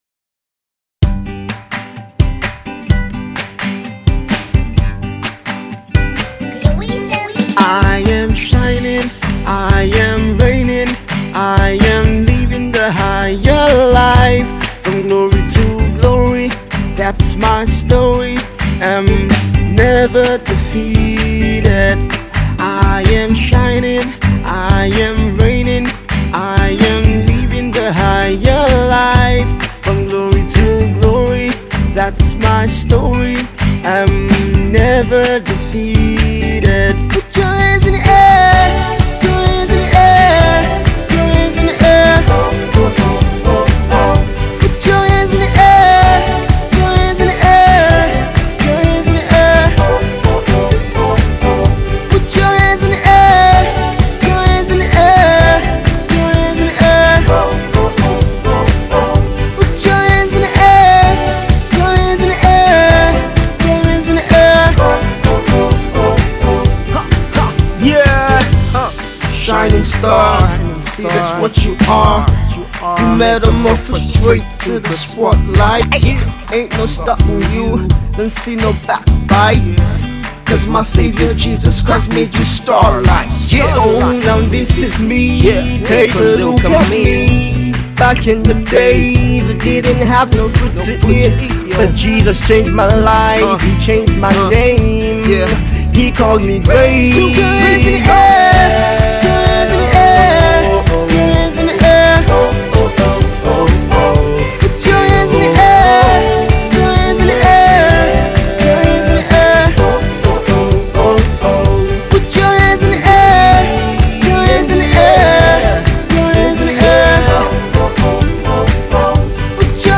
Pop …
is a certified party-banger